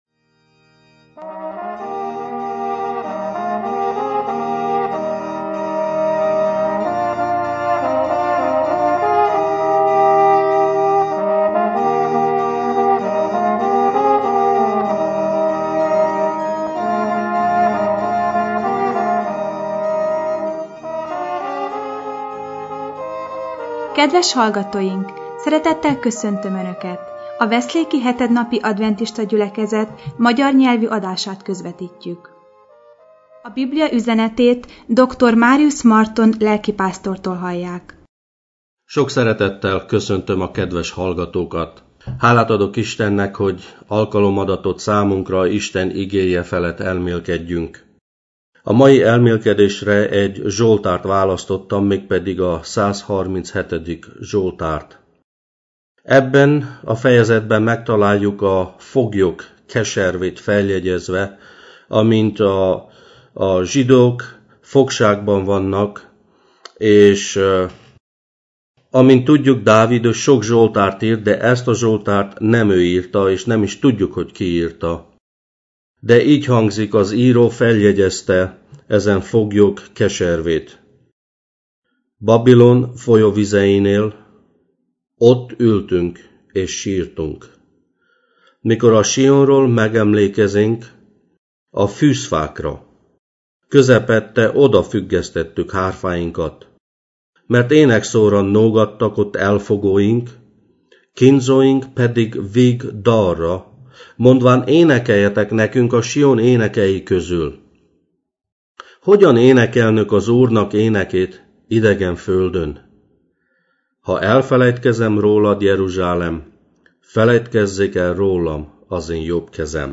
első igehirdetése a Bocskai Rádióban: